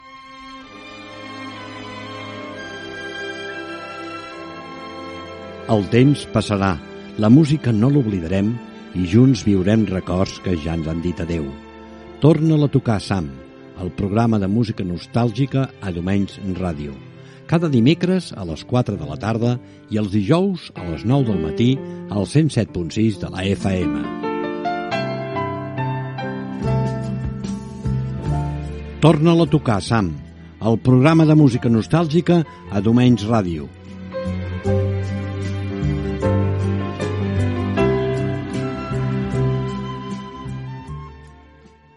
51e3c56b1f24ca312fc2da2f319a6e76d2fca002.mp3 Títol Domenys Ràdio Emissora Domenys Ràdio Titularitat Pública municipal Nom programa Torna-la a tocar Sam Descripció Promoció del programa de música nostàlgica.